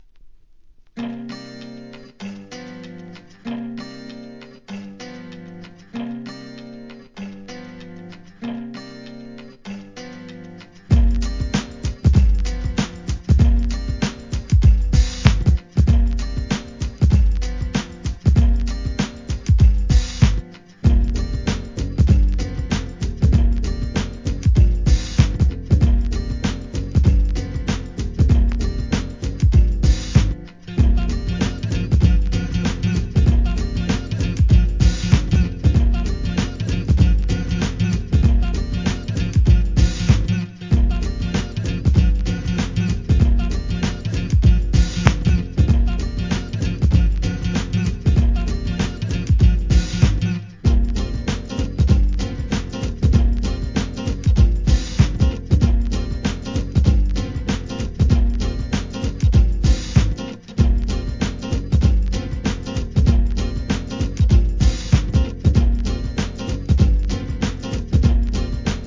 HIP HOP/R&B
FUNKYなJAZZYブレイクにJAZZY HOUSEを収録！